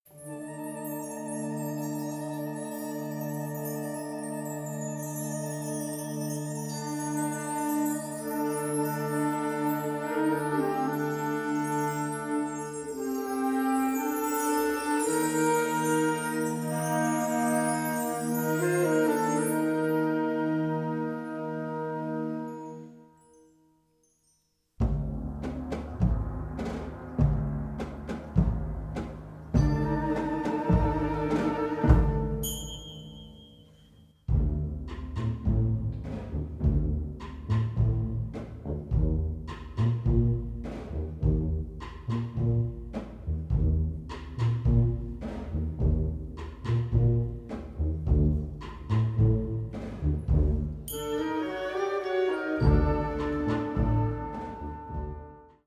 Categorie Harmonie/Fanfare/Brass-orkest
Subcategorie Concertmuziek
Bezetting Ha (harmonieorkest); YB (jeugdorkest)